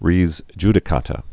(rēz jdĭ-kätə, rās)